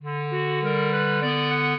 clarinet
minuet4-3.wav